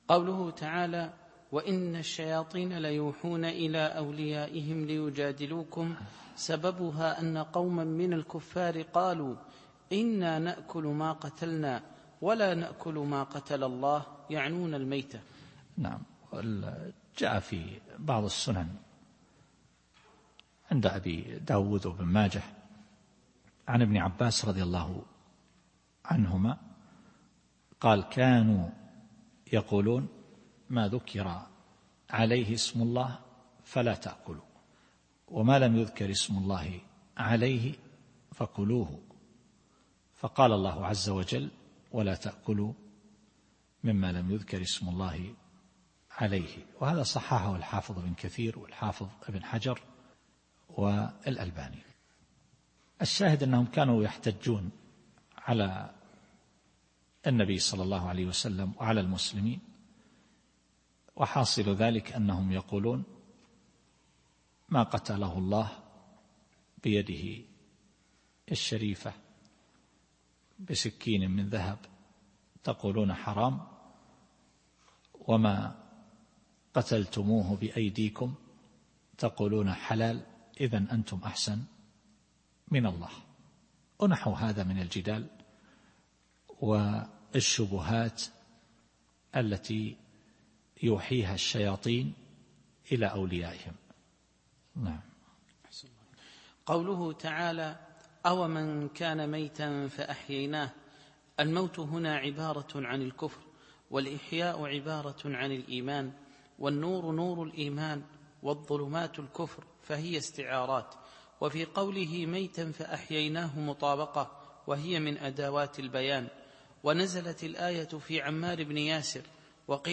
التفسير الصوتي [الأنعام / 121]